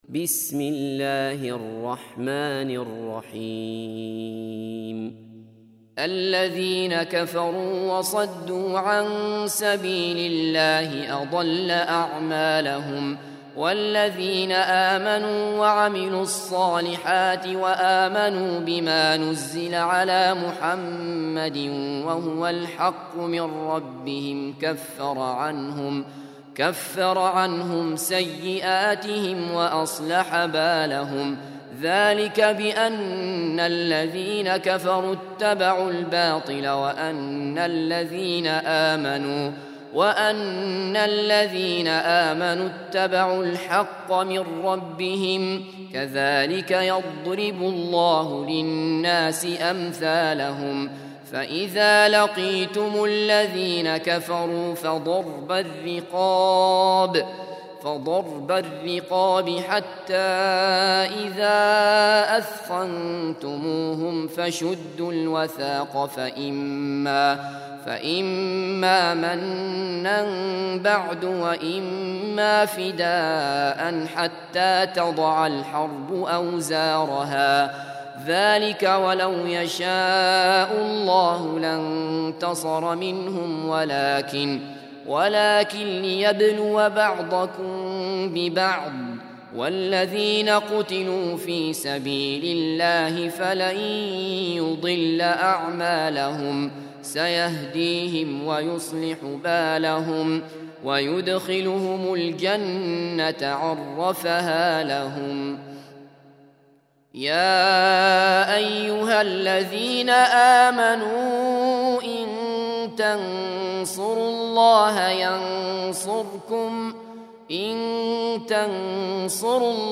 Surah Repeating تكرار السورة Download Surah حمّل السورة Reciting Murattalah Audio for 47. Surah Muhammad or Al-Qit�l سورة محمد N.B *Surah Includes Al-Basmalah Reciters Sequents تتابع التلاوات Reciters Repeats تكرار التلاوات